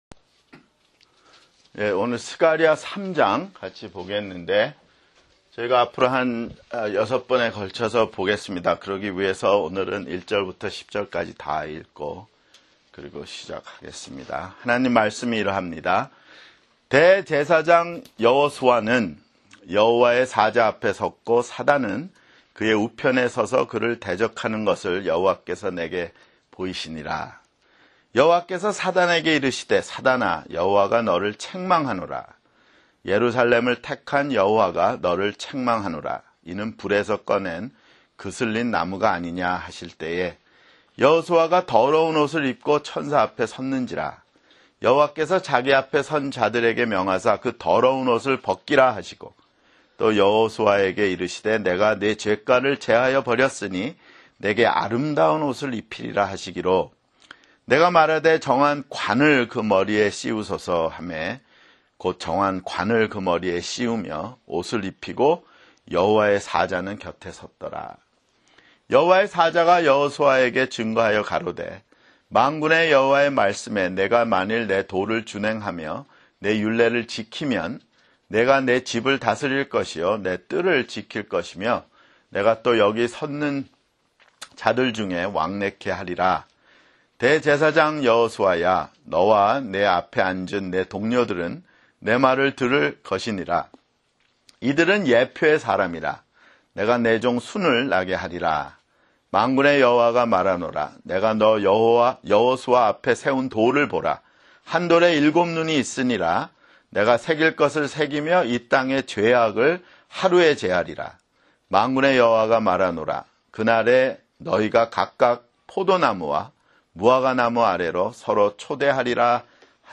[성경공부] 스가랴 (18)